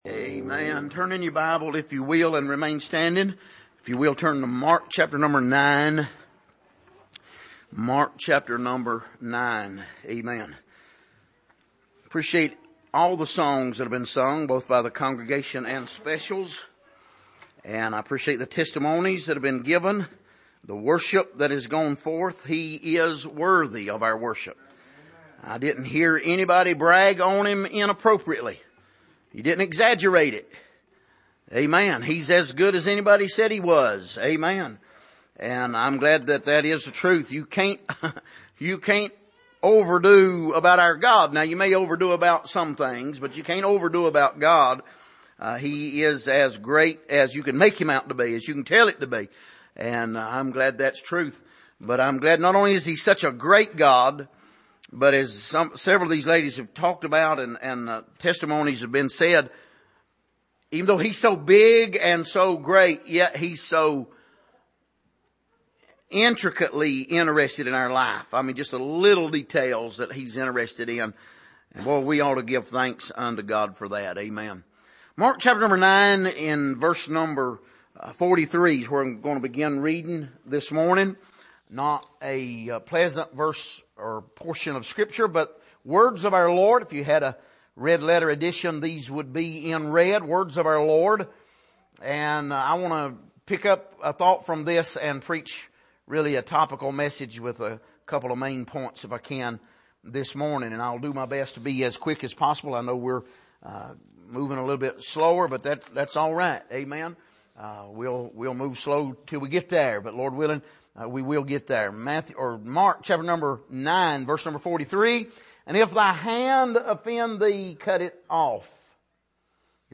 Passage: Mark 9:43-48 Service: Sunday Morning